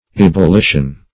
Ebullition \Eb`ul*li"tion\, n. [F. ['e]bullition, L. ebullitio,